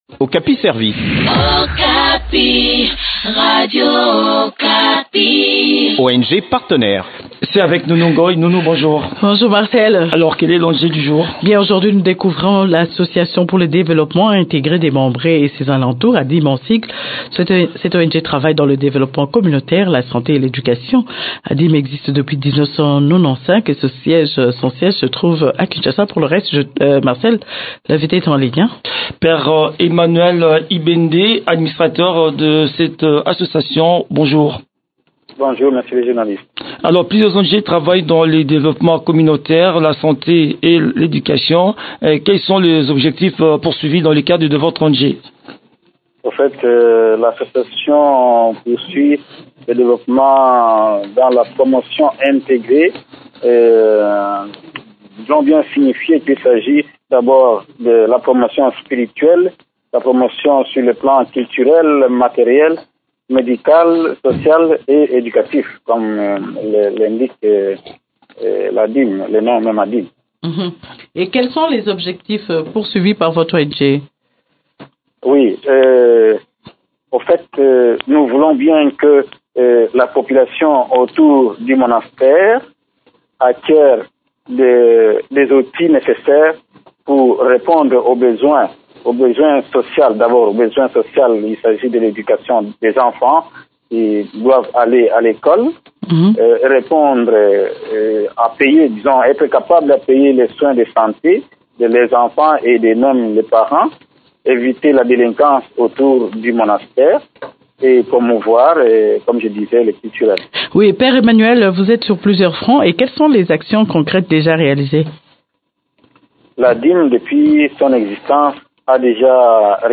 Elle exécute aussi des projets dans les secteurs de la santé et de l’éducation. Le point des activités de cette structure dans cet entretien